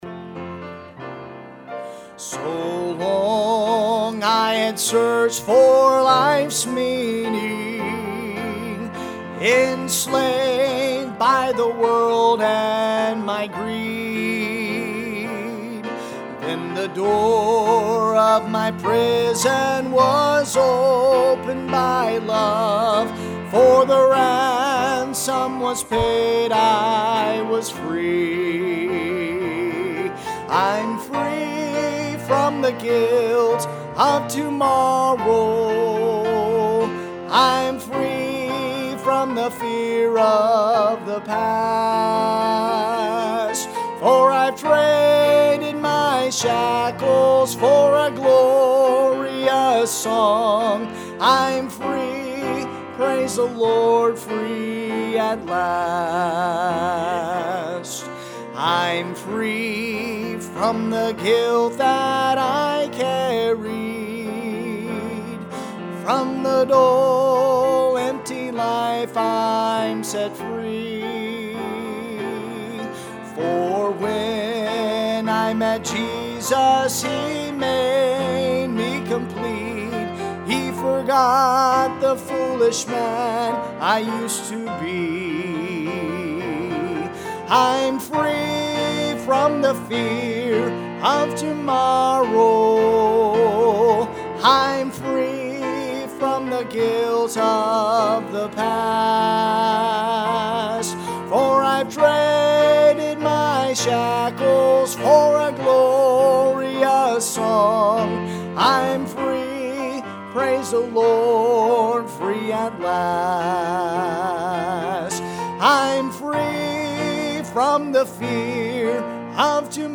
Good Steward | Sunday AM – Shasta Baptist Church